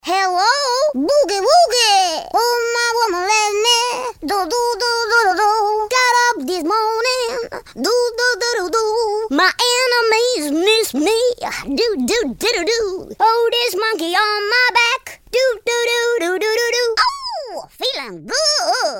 • Качество: 192, Stereo
позитивные
веселые
забавный голос
детский голос
акапелла
Смешной голос напевает какую-то знакомую песенку.